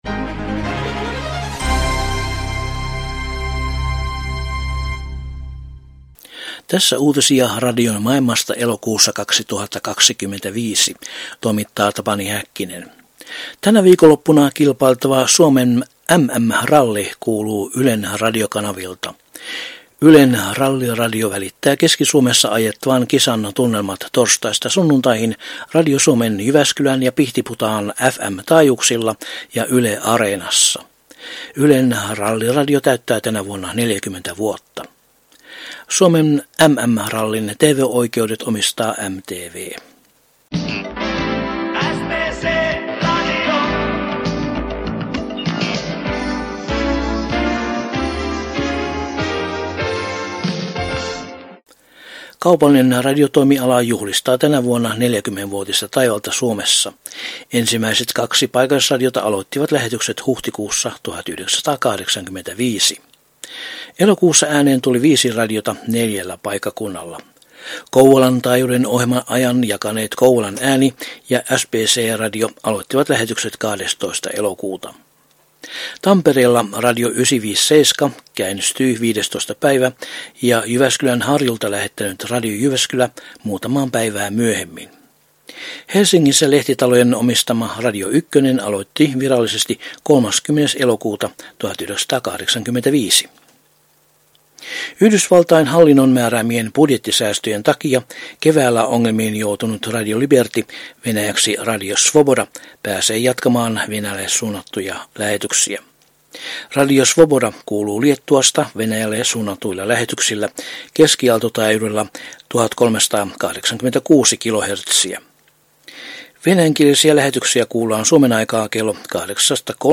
Elokuun 2025 uutislähetyksessä aiheina ovat muun muassa Ylen Ralliradio ja Venäjälle lähetyksiä Liettuasta suuntaavan Radio Svobodan paluu radioaalloille.